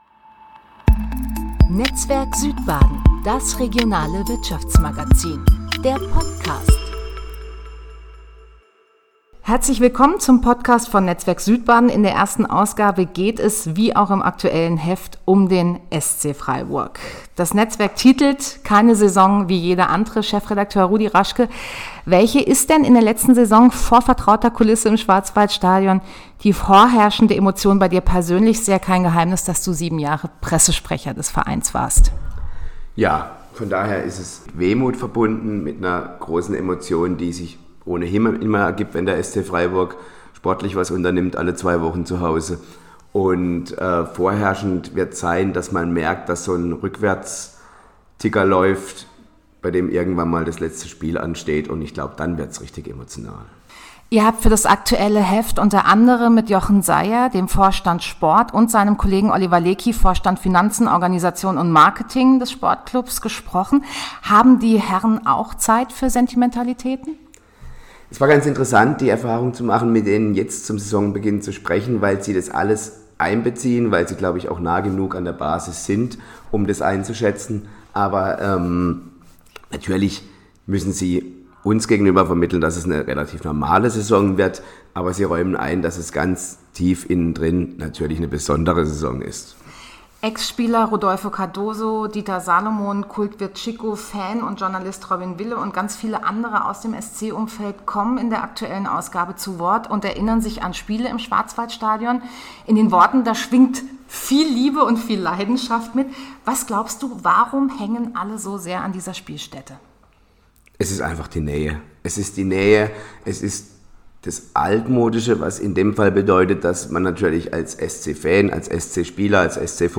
Zudem kommt Freiburgs Finanzbürgermeister Stefan Breiter zu Wort, der über seine Verbindung zum Verein sowie die Bedeutung des neuen Stadions spricht.